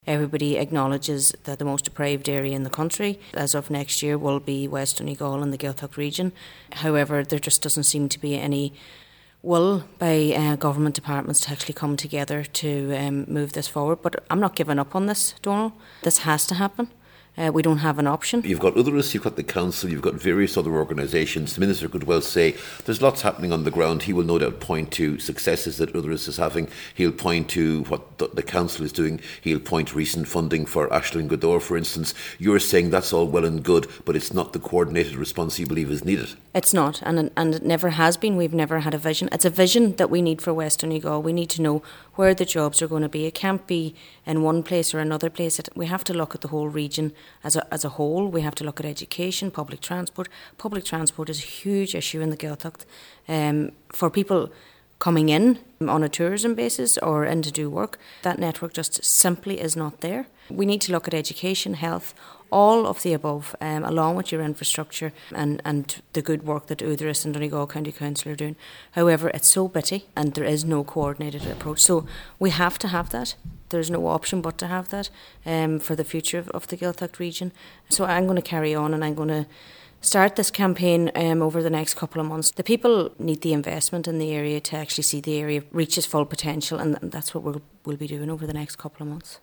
As of yet, there’s been little progress made, but Cllr Gallagher says she will be stepping up her campaign…..